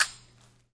标签： Hate The Hop Percussion Nova Beat Drums Drum Erace Sound Hip
声道立体声